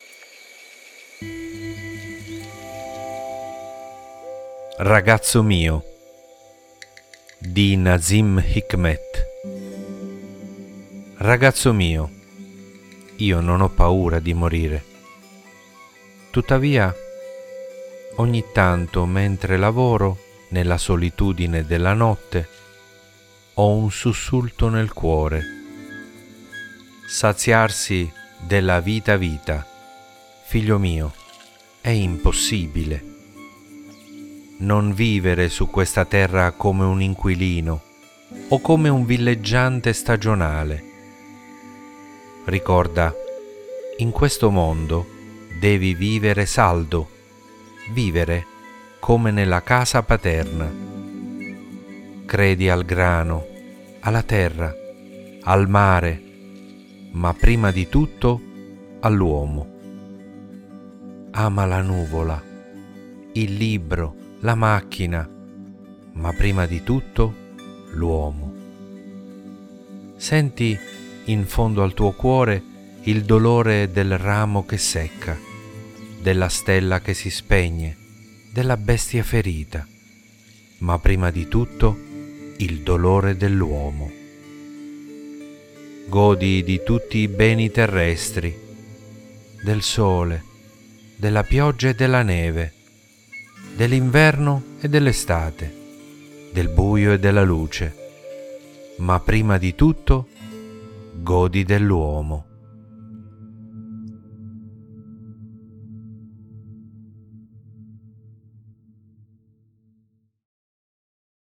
Leggiamo insieme la struggente poesia “ragazzo mio” di Nazim Hikmet